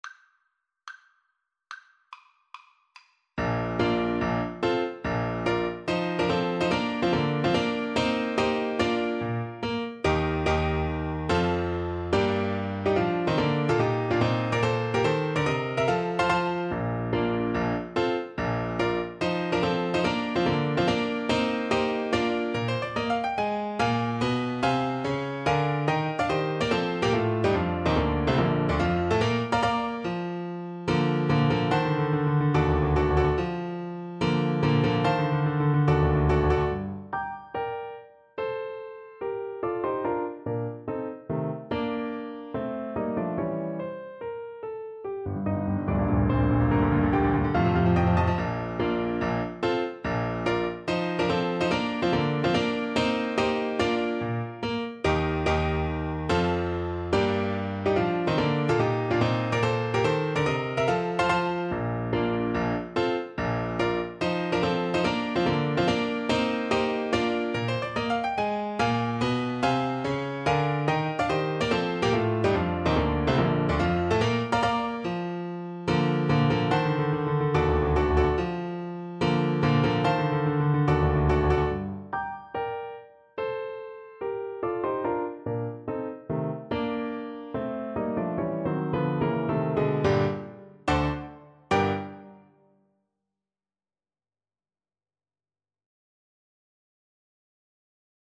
Play (or use space bar on your keyboard) Pause Music Playalong - Piano Accompaniment Playalong Band Accompaniment not yet available transpose reset tempo print settings full screen
Allegro non troppo (=72) (View more music marked Allegro)
Bb major (Sounding Pitch) (View more Bb major Music for Cello )
Classical (View more Classical Cello Music)